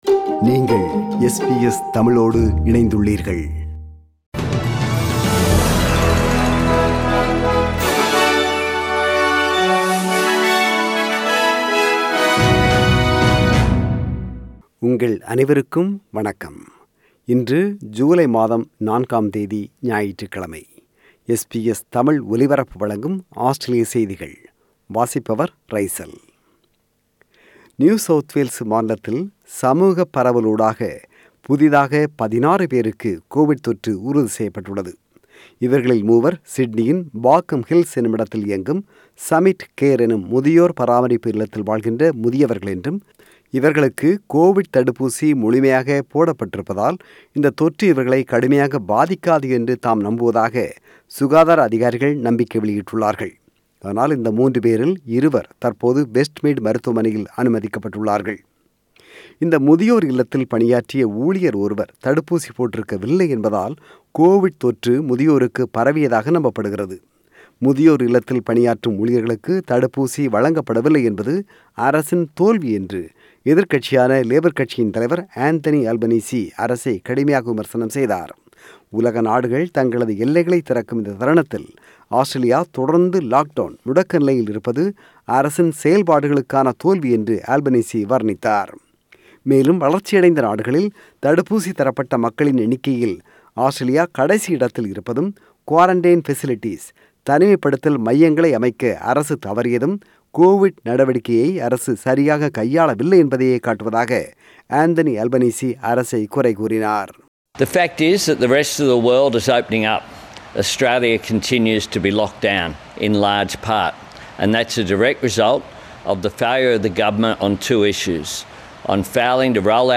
ஆஸ்திரேலிய செய்திகள்: 4 ஜூலை 2021 ஞாயிற்றுக்கிழமை வாசித்தவர்